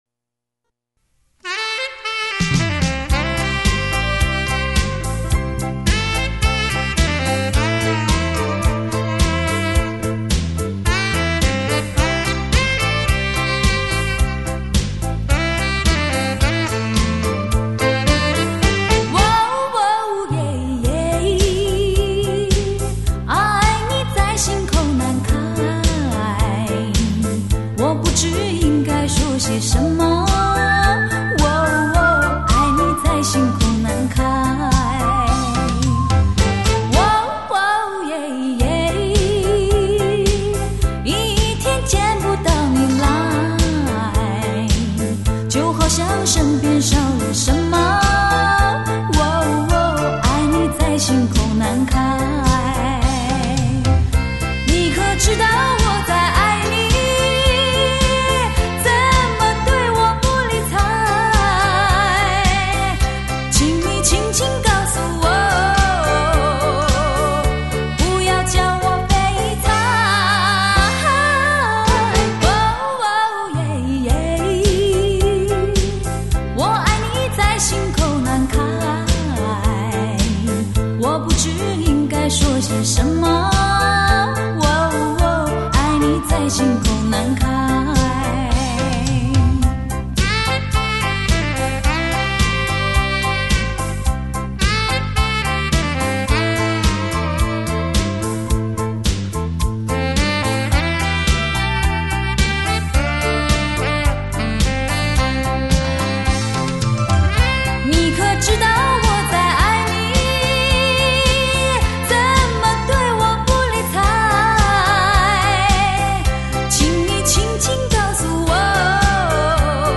迪斯可